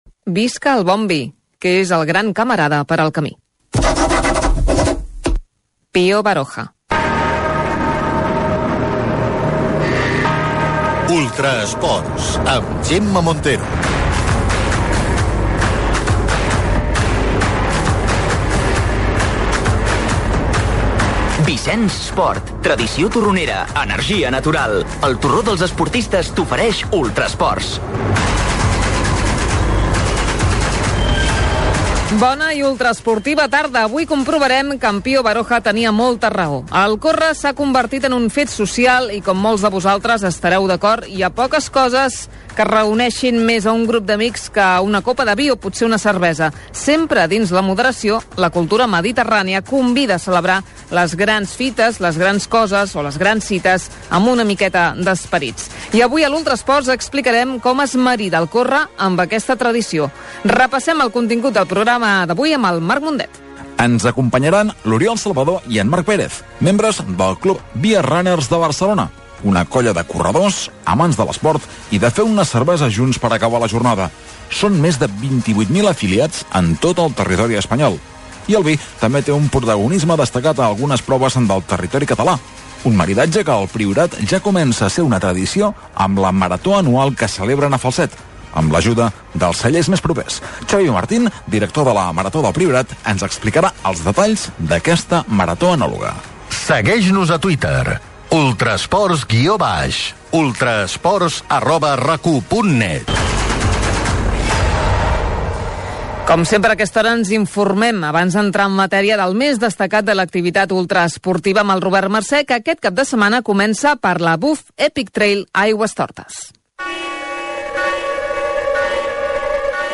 Frase de Pío Barjoja, careta del programa, sumari de continguts, compte de Twitter del programa, informació de la cursa Buf Trail d'Aigües Tortes
Esportiu